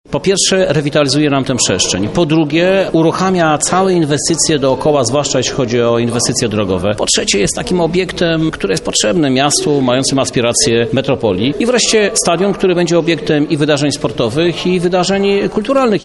O korzyściach płynących z tej inwestycji, mówi Krzysztof Żuk, prezydent miasta